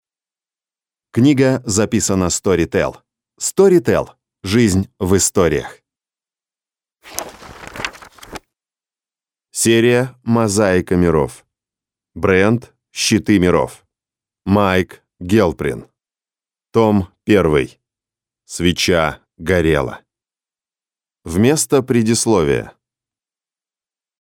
Аудиокнига Мозаика Миров. Щиты миров. Свеча горела | Библиотека аудиокниг